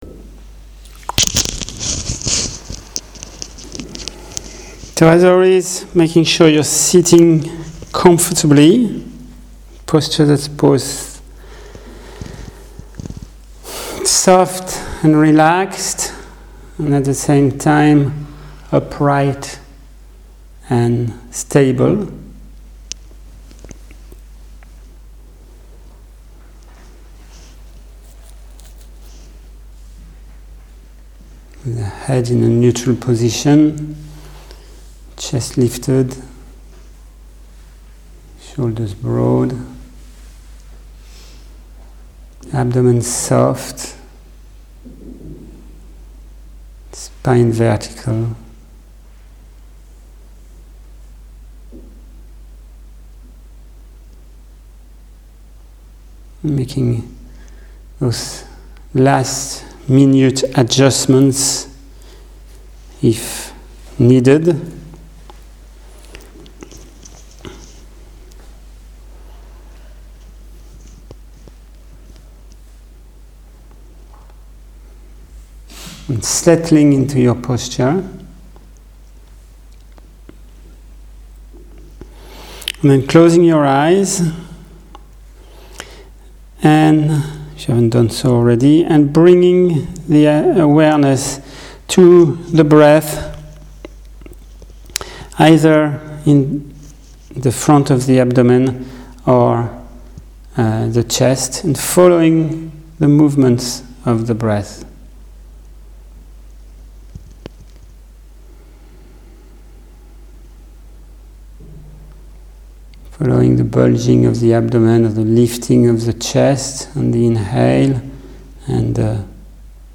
Click here for a guided pracice (audio)